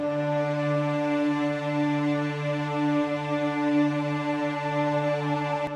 piano-sounds-dev
d5.wav